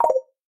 Genel olarak telefonun güçlü özelliklerini yansıtan polifonik ve güçlü tonların bulunduğu bildirim seslerinin firmanın yıllardır süregelen seslerin modern yorumlarını içermektedir.